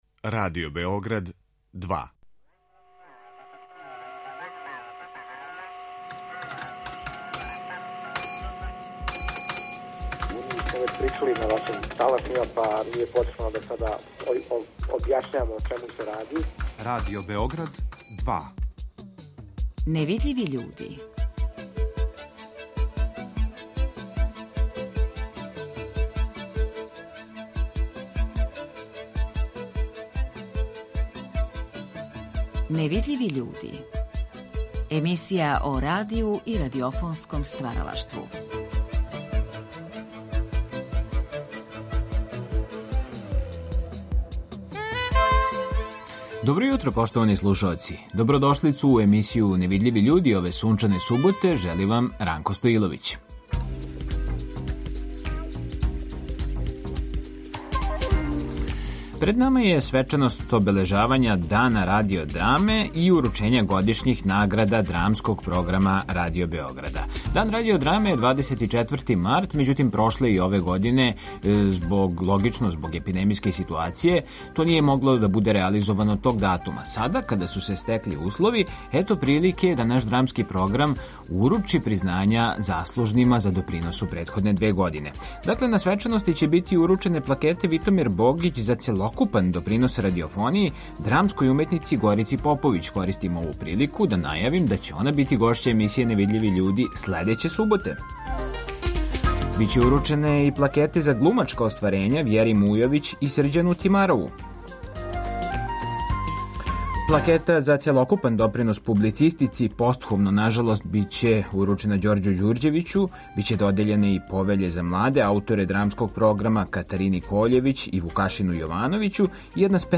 Гост емисије „Невидљиви људи" је један од овогодишњих добитника Повеље „Витомир Богић" за годишњи допринос радиофонији, редитељ Горчин Стојановић.